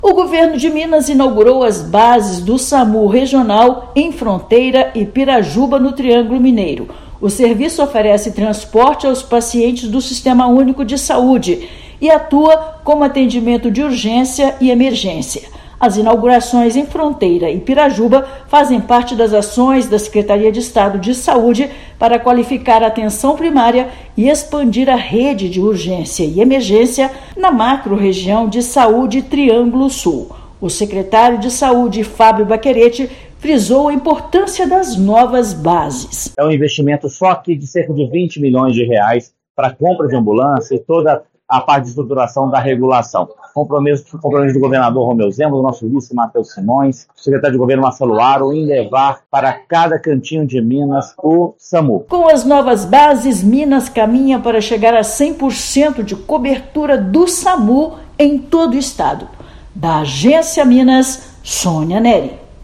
[RÁDIO] Governo de Minas inaugura bases do Samu no Triângulo e caminha para 100% de cobertura no estado
Expansão do serviço para Fronteira e Pirajuba amplia capacidade de prestação de primeiros socorros. Ouça matéria de rádio.